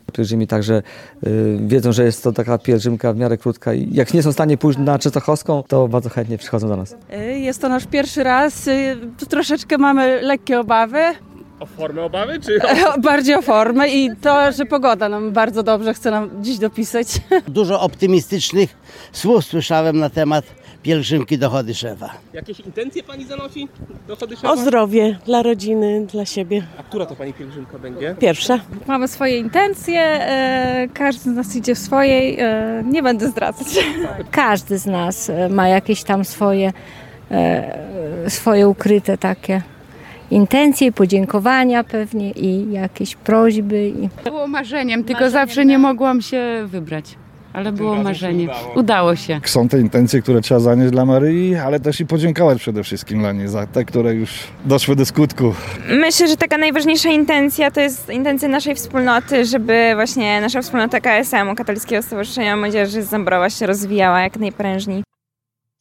Pielgrzymka, w której udział bierze ponad 200 osób, rozpoczęła się od mszy świętej w kościele pw. Trójcy Przenajświętszej w Zambrowie pod przewodnictwem biskupa pomocniczego Diecezji Łomżyńskiej Tadeusza Bronakowskiego.
Więcej w naszej relacji: